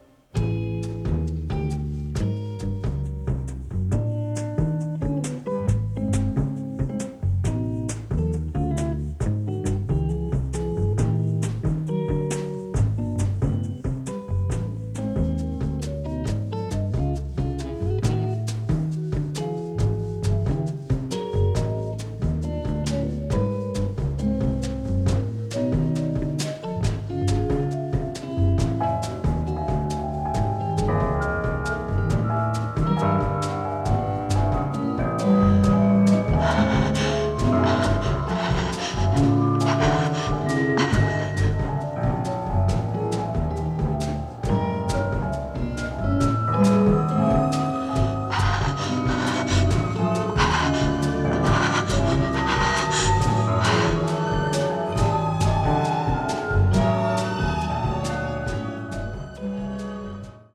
sophisticated avant-garde sound